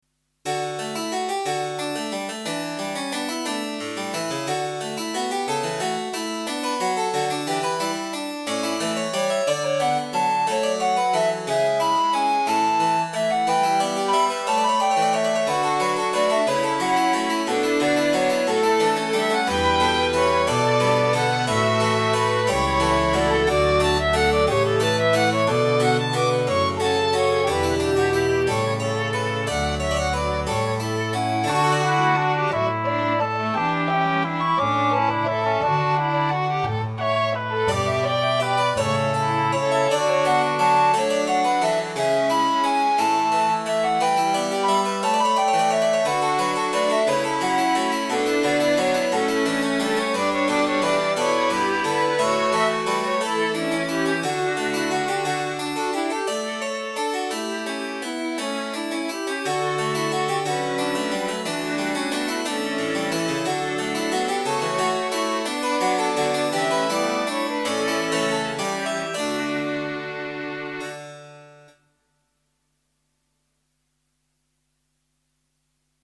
Een eenvoudige opzet van klavecimbel, fluit, cello, hobo, viool en altviool.
Ik hou van de rustieke, zondagse sfeer welke die muziek uit de recente oudheid uitstraalt.